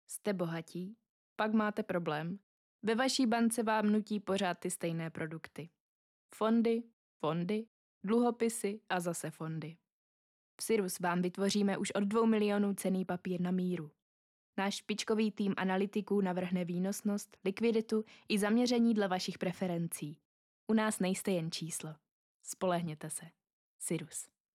Profesionální ženský hlas - voiceover/dabing (do 400 znaků)